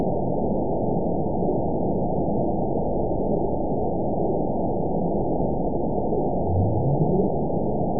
event 920526 date 03/28/24 time 22:10:37 GMT (1 year, 1 month ago) score 9.51 location TSS-AB02 detected by nrw target species NRW annotations +NRW Spectrogram: Frequency (kHz) vs. Time (s) audio not available .wav